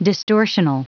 Prononciation du mot distortional en anglais (fichier audio)
Prononciation du mot : distortional